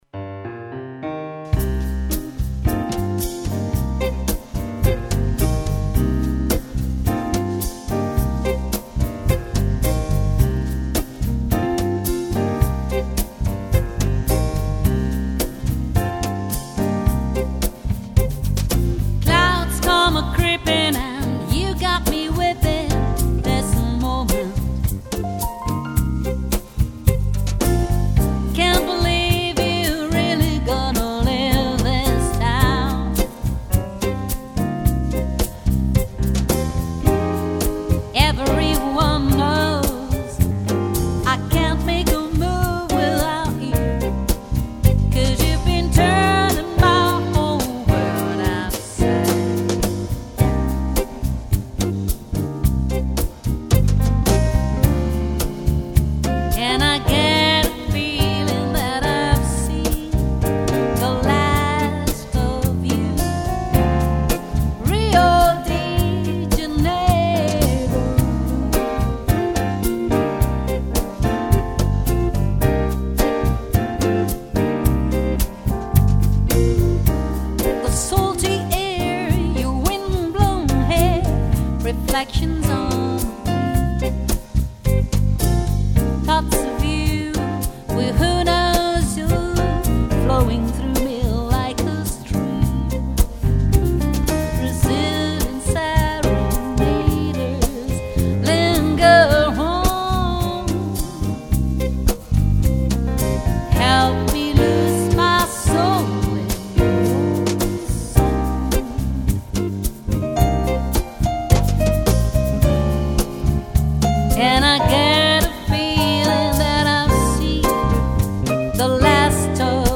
GenereBlues